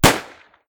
sniper.ogg